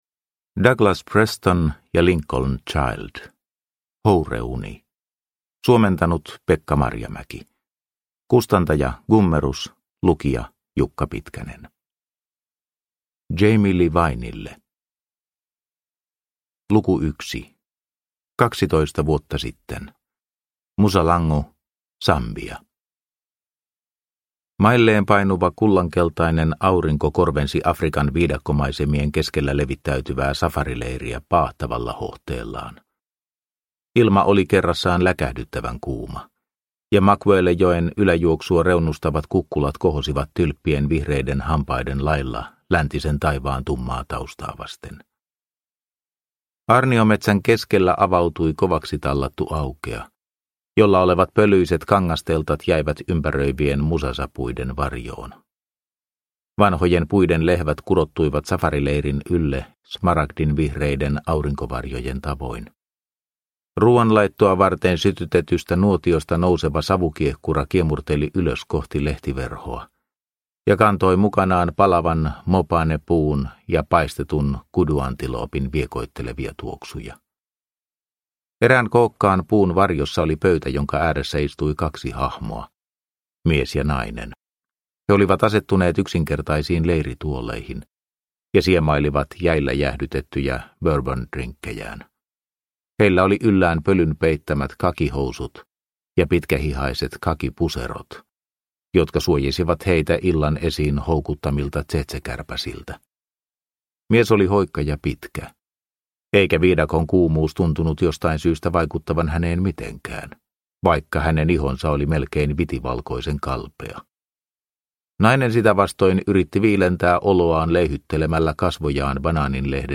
Houreuni – Ljudbok – Laddas ner